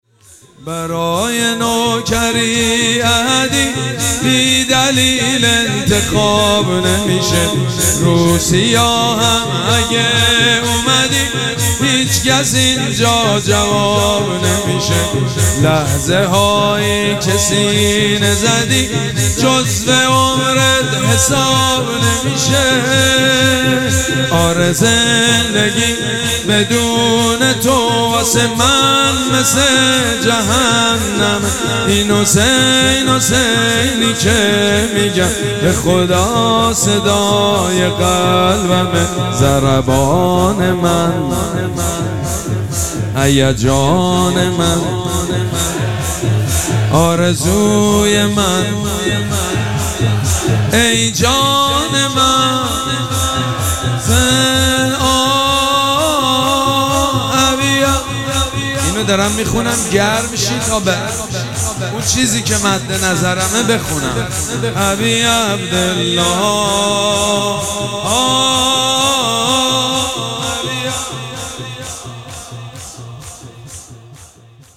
شب سوم مراسم عزاداری اربعین حسینی ۱۴۴۷
شور
مداح
حاج سید مجید بنی فاطمه